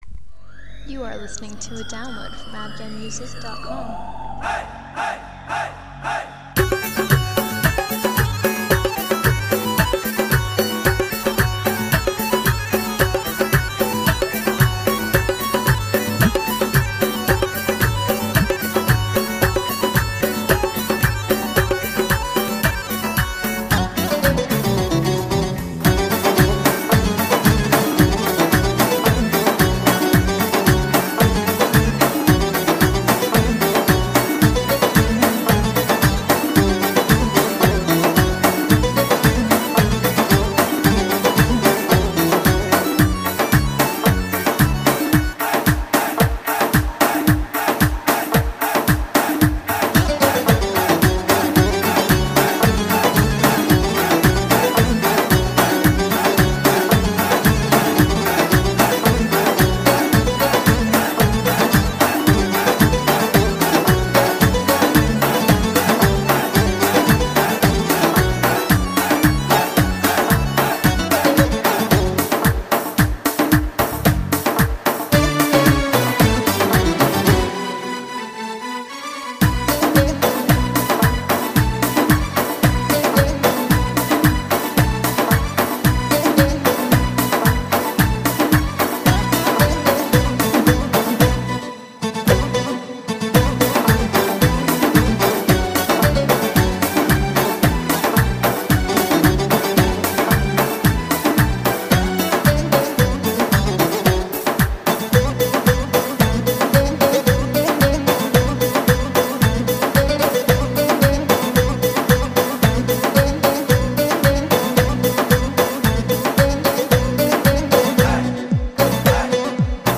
10.Instrumental